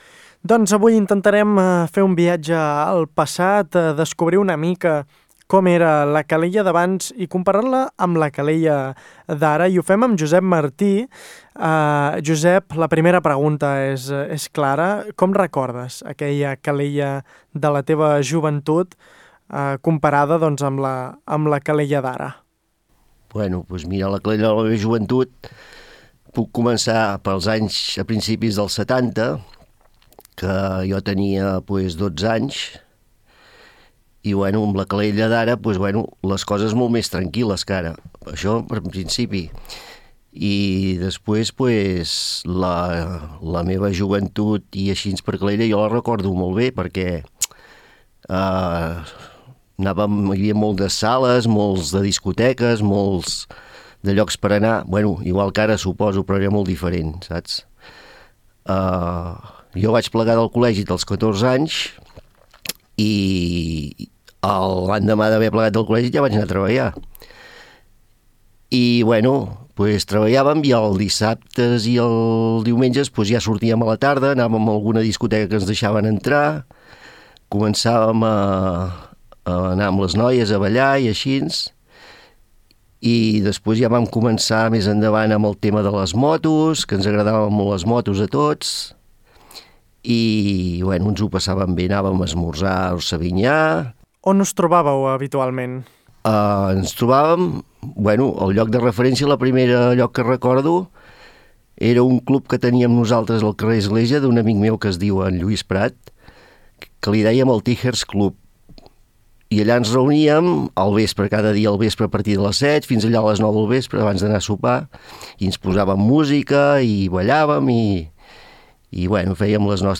Una conversa que connecta memòria, identitat i present.